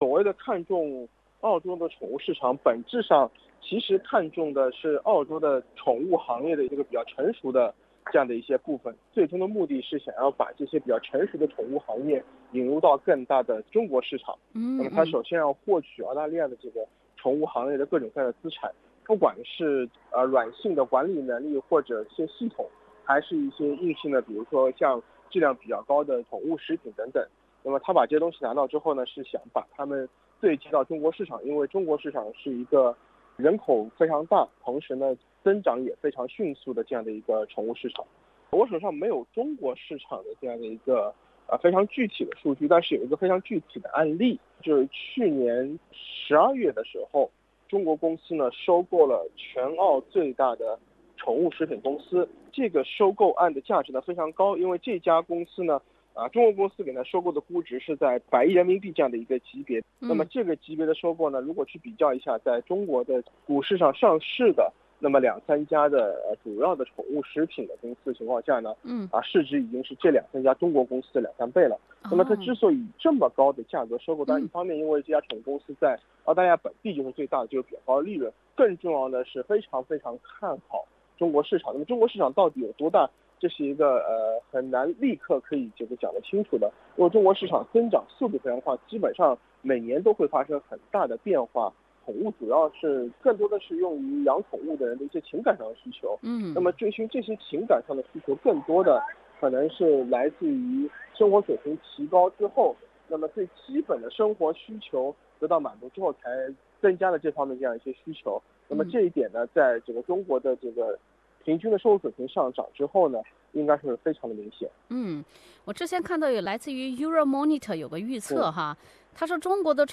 Chinas pet economy is promising, with Chinese investors aiming in the Australia's pet market. Interview